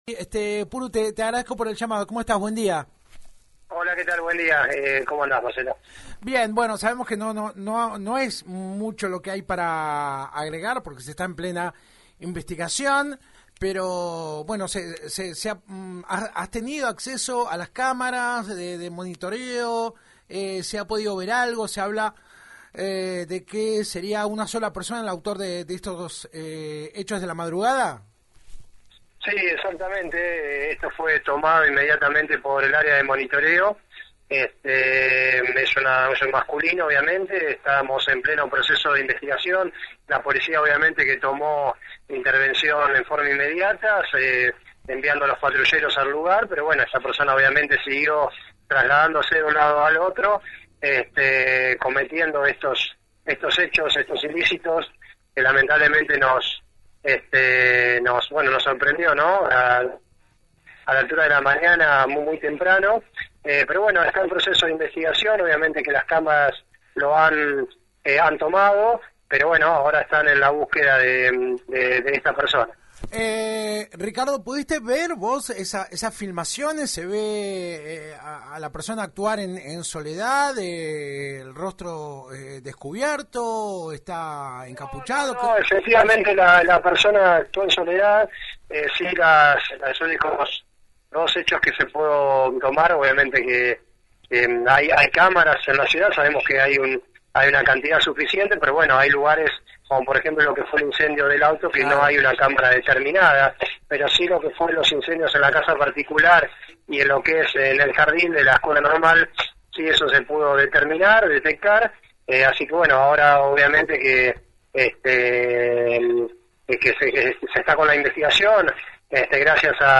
Luego de los hechos vandálicos de esta madrugada la 91.5 habló con el subsecretario de Gobierno, quien contó que «estamos en pleno proceso de investigación.